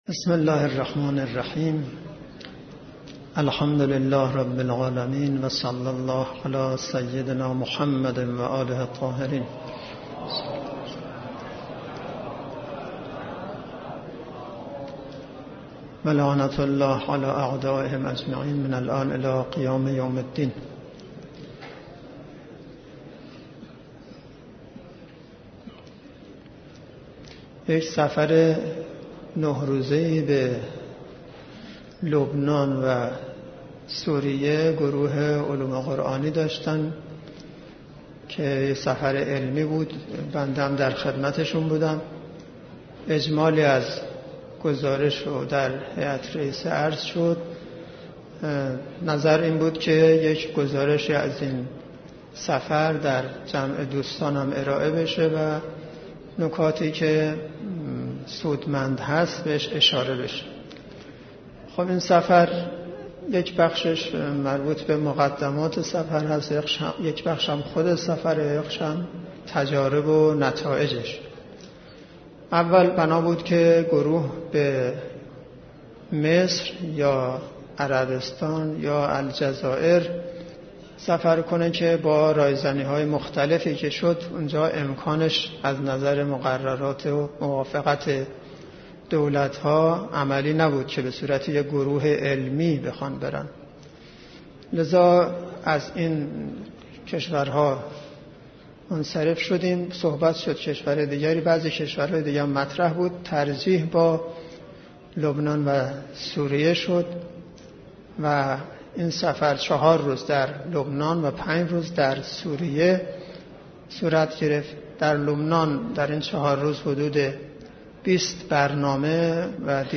مجموعه صوتي سخنراني بعد از زيارت عاشوراي